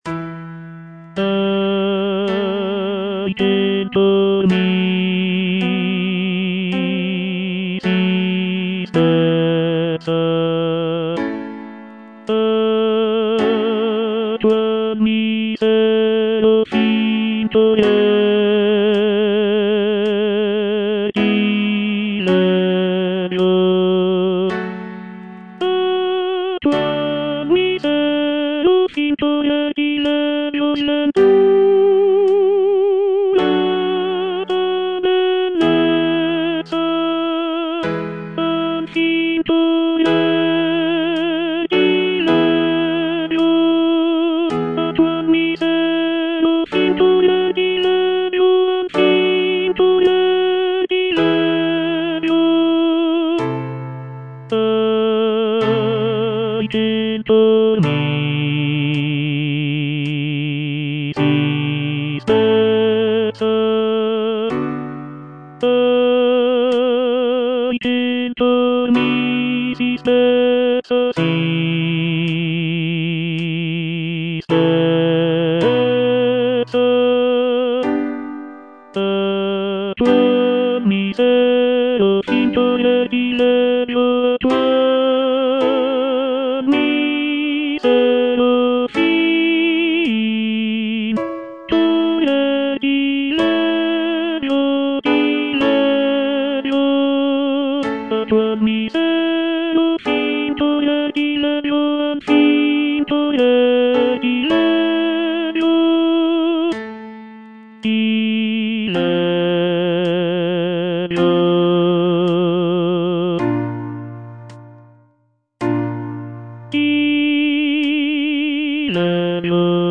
C. MONTEVERDI - LAMENTO D'ARIANNA (VERSION 2) Coro II: Ahi! che'l cor mi si spezza - Tenor (Voice with metronome) Ads stop: auto-stop
It is a deeply emotional lament aria that showcases the singer's ability to convey intense feelings of grief and despair.
The music is characterized by its expressive melodies and poignant harmonies, making it a powerful and moving example of early Baroque vocal music.